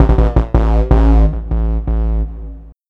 05Sucker 165bpm Dm.wav